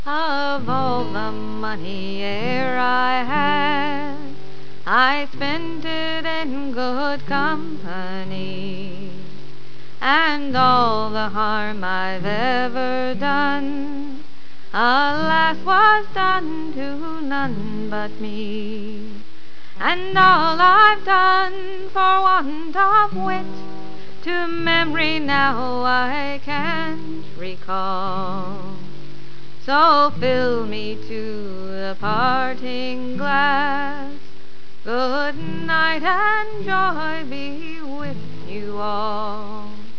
Aye, 'tis a traditional Irish song for singing